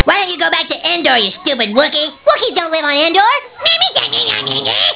- SouthPark Cartman: "Why don't you Endor you stupid wookie?" Stan: "Wookies don't live on Endor!" Cartman: *gross exageration of what Stan said, unspellable, I think ^_^*